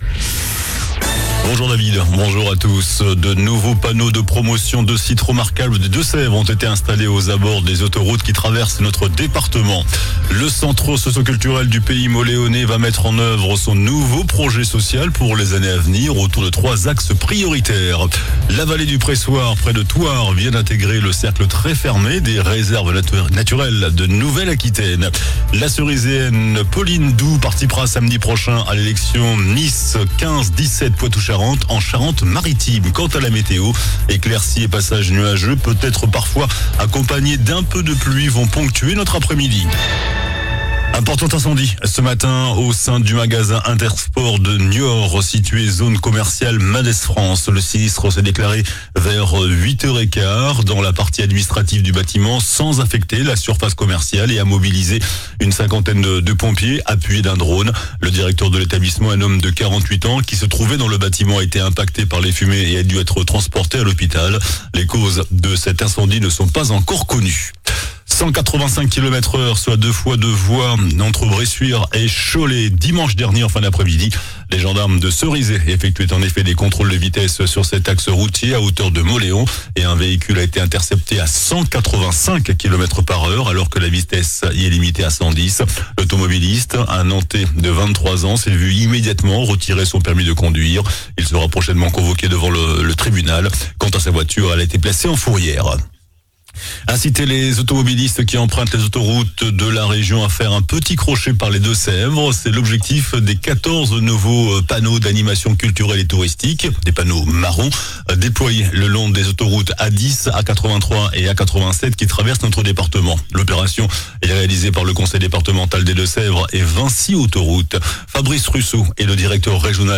JOURNAL DU MARDI 15 AVRIL ( MIDI )